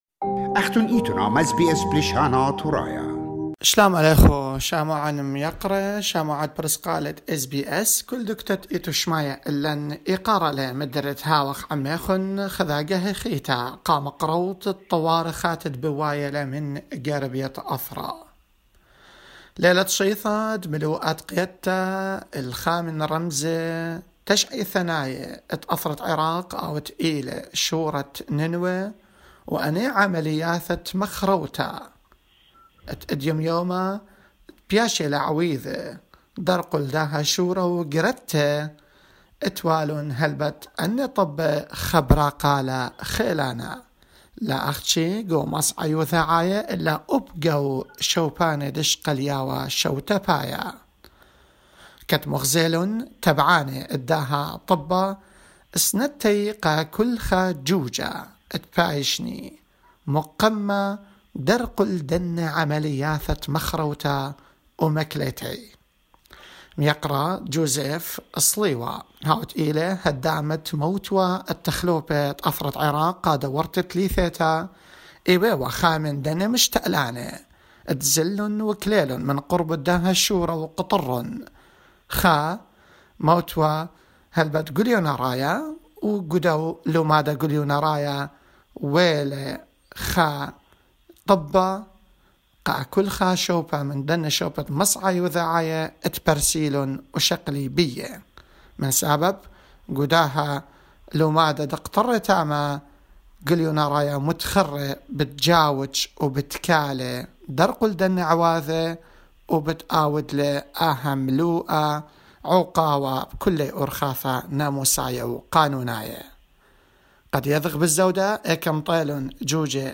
Former Iraqi MP Joseph Sliwa provides an update on his campaign urging authorities in Mosul to abandon a road expansion project which has destroyed a section of the ancient Wall of Nineveh.